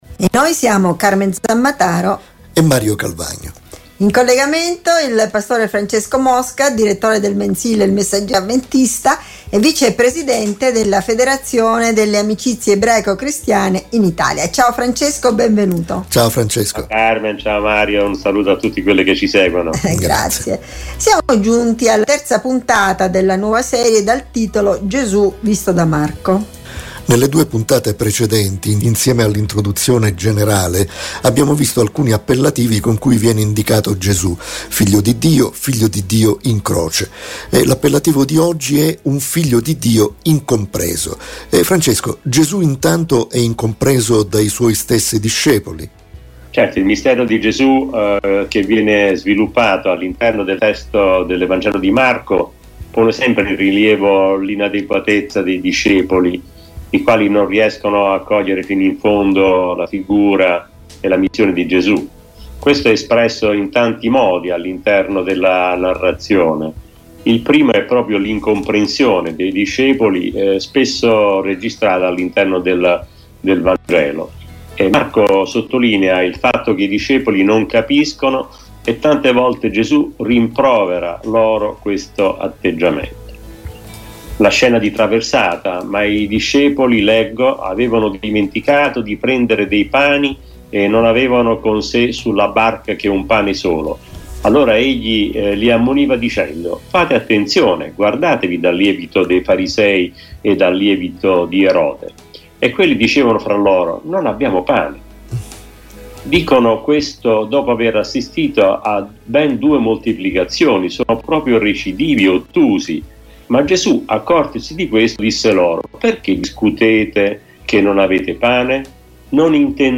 ne parlano con il pastore